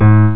INTERACTIVE PIANO
the note should sound out.